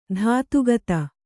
♪ dhātu gata